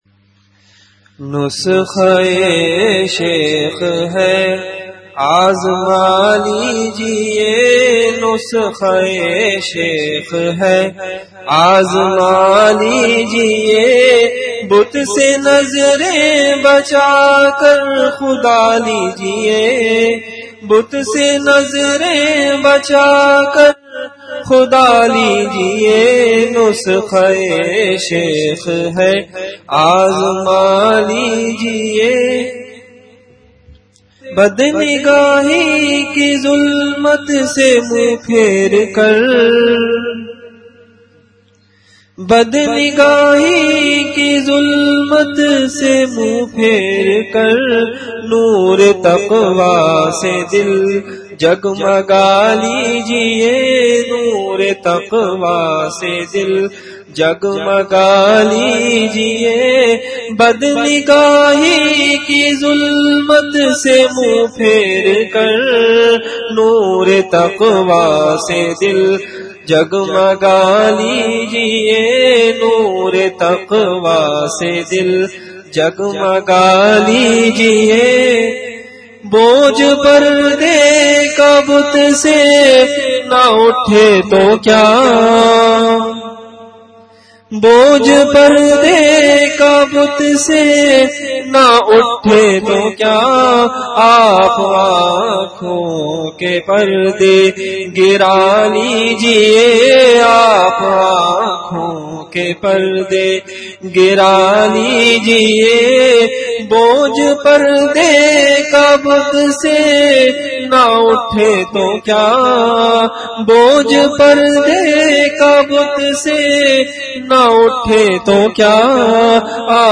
Venue Home Event / Time After Isha Prayer